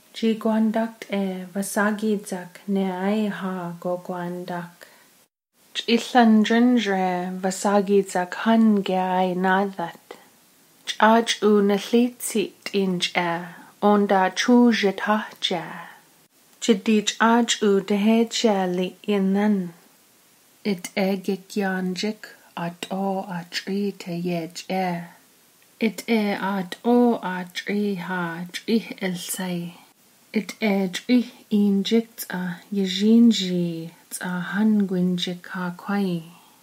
23 July 2017 at 11:07 am The numerous affricates, ejectives, and lateral fricatives, plus the simple vowel system, the lack of labial stops, and what sounds like high vs low tones make me immediately think of Athabaskan. But the noticeable retroflexes narrow things down a bit.